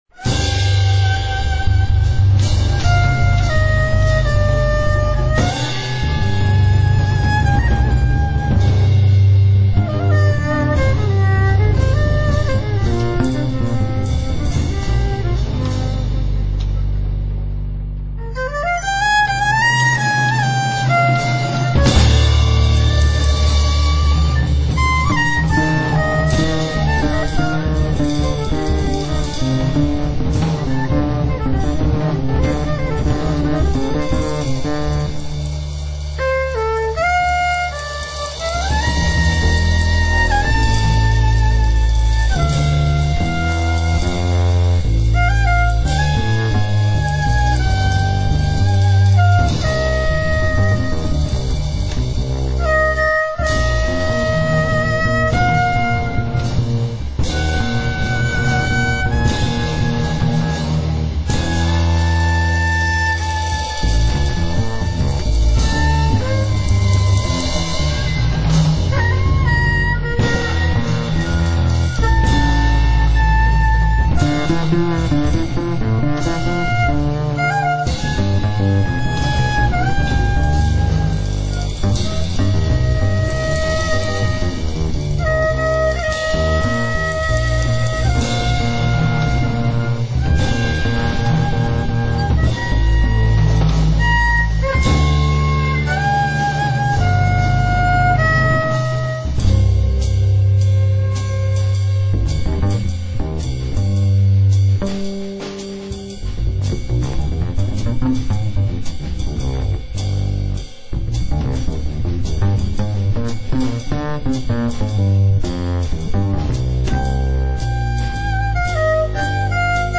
violin, percussions, vocal
trombone
doublebass
drums
at The Mascherona Studio, Genova - Italy